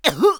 traf_damage5.wav